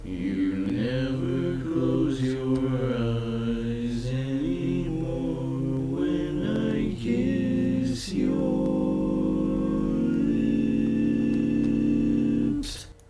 Key written in: C Major
How many parts: 4
Type: Other male
All Parts mix: